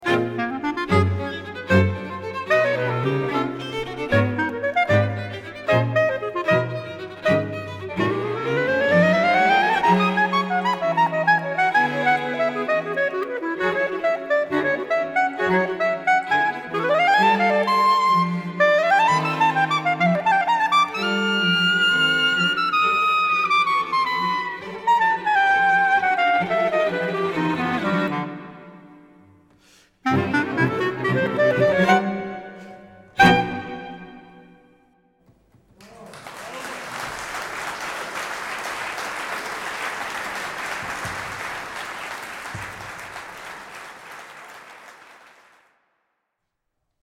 klarinet
Klarinette und Streichquartett